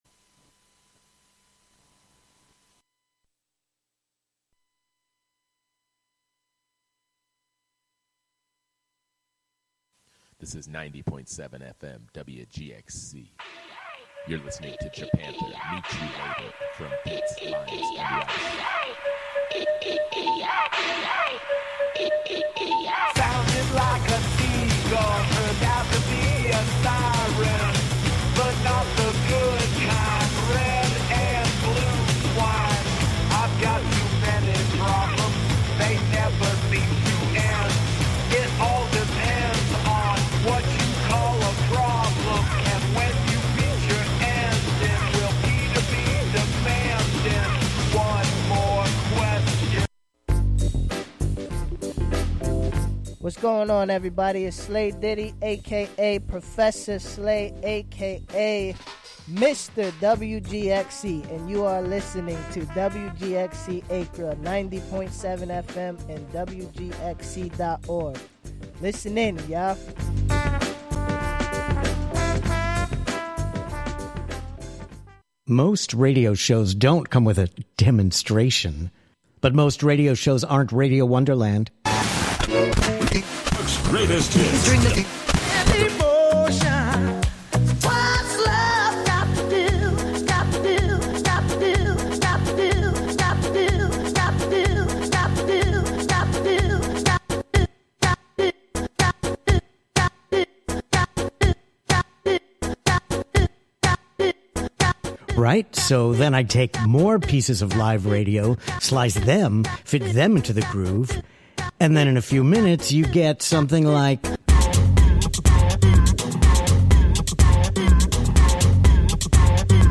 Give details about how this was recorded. broadcast live from WGXC's Catskill studio.